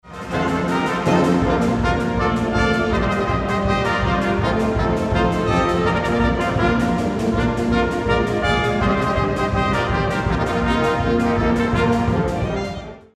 Audiobeispiel eines Trompetensatzes
Audiobeispiel Trompetensatz
trompetensatz.mp3